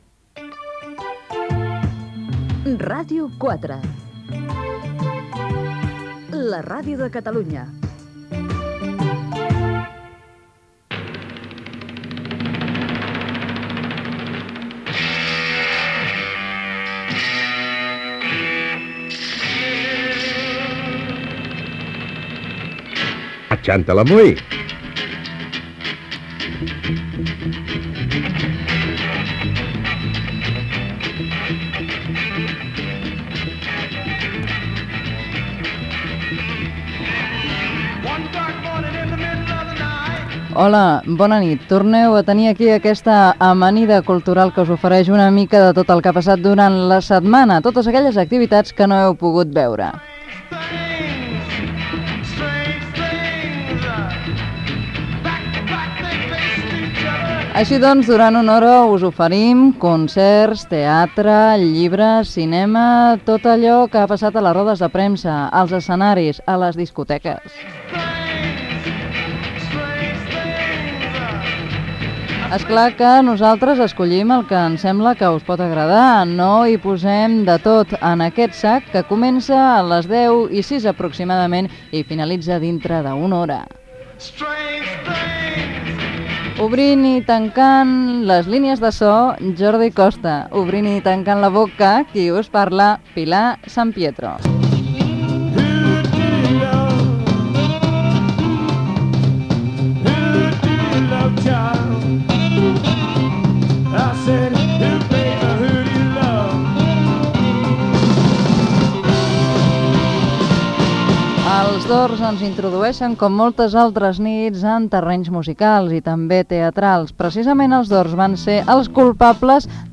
Indicatiu de l'emissora, careta, presentació, equip, tema musical, presentacions de versions d'un mateix tema musical i informació de l'espectacle del Teatre Malic de Barcelona
FM